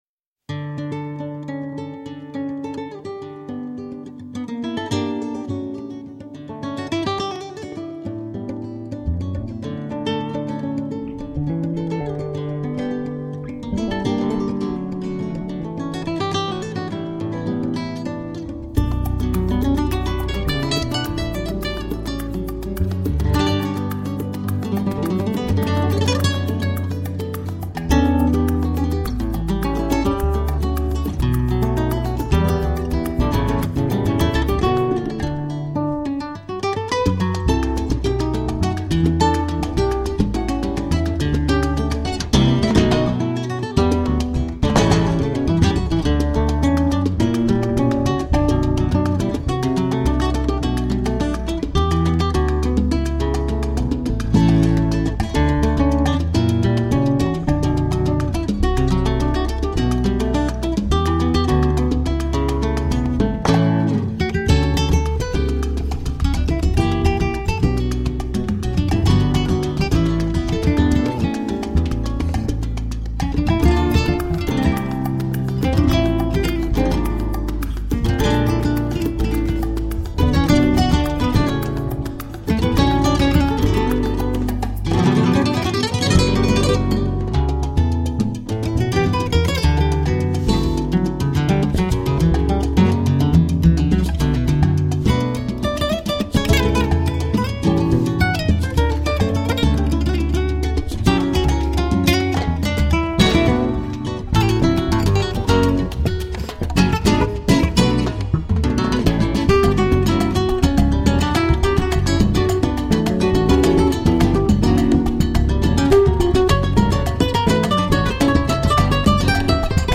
Filed under Flamenco Tagged with ,